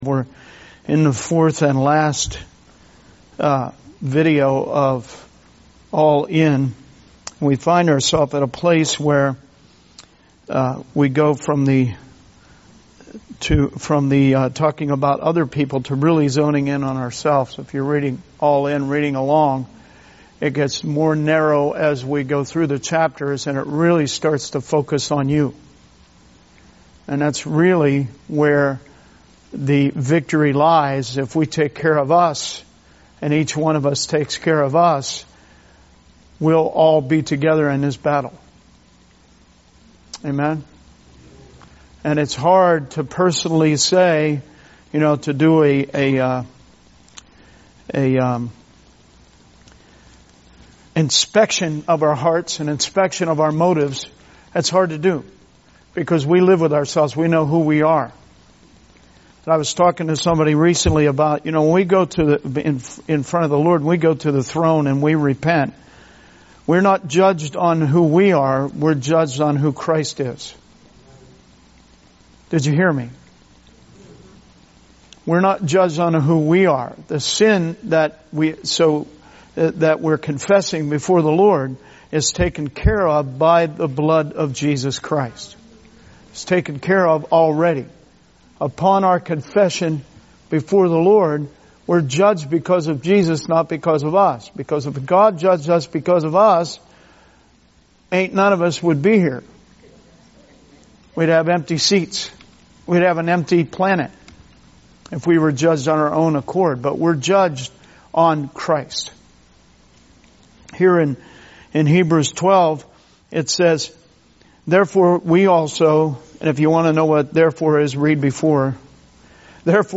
Sermon messages available online.
All In Service Type: Wednesday Teaching Preacher